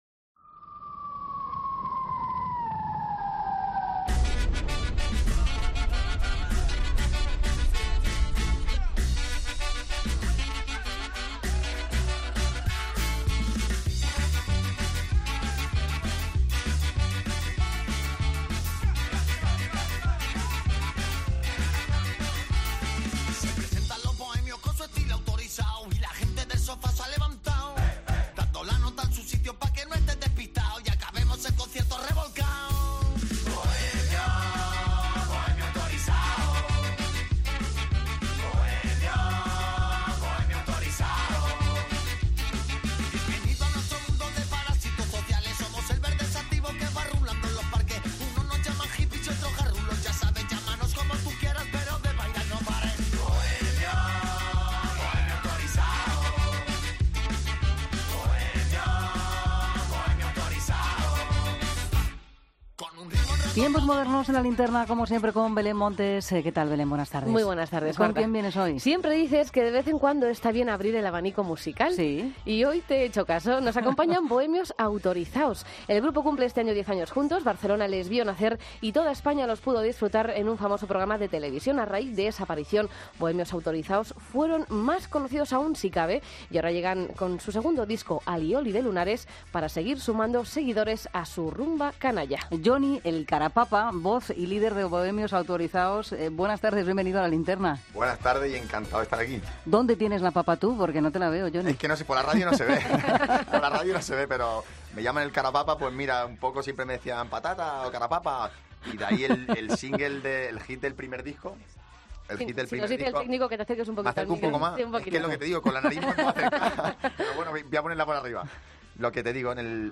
Entrevista a Bohemios Autorizaos en La Linterna